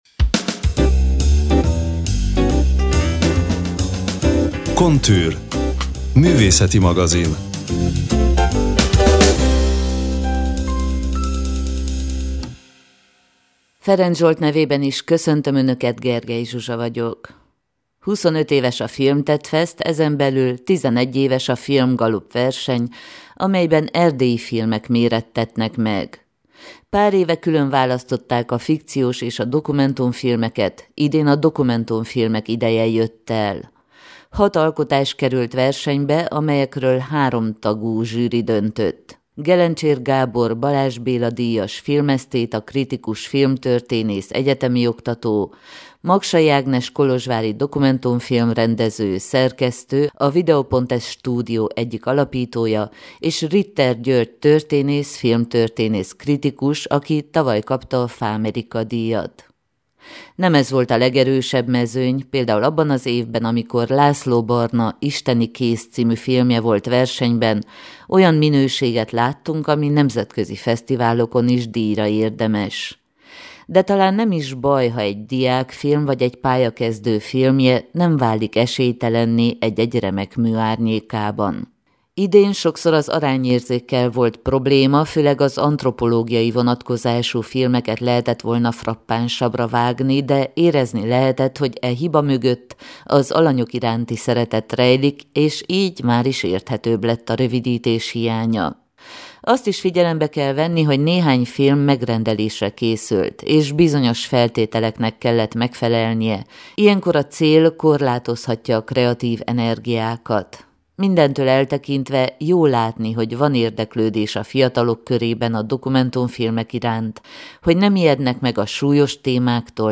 A 11. Filmgalopp nyertes alkotásainak készítőivel beszélgettünk.